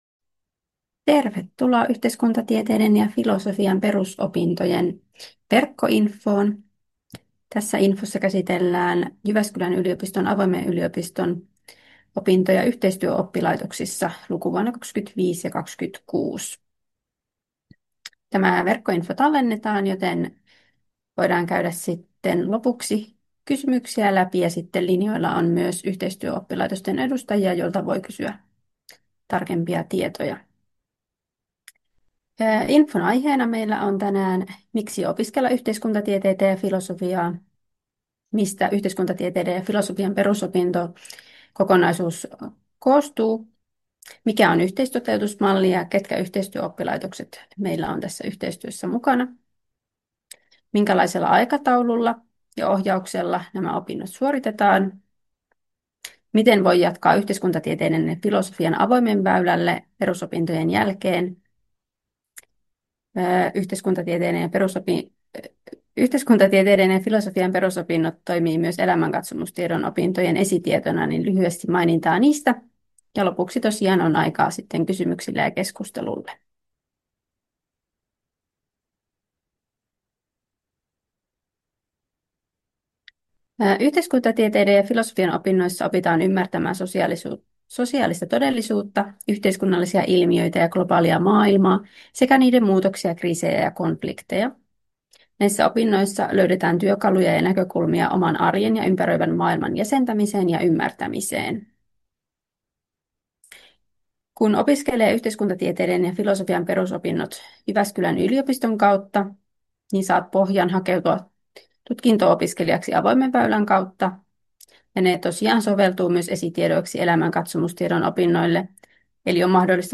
12.8.2025 pidetty infotilaisuus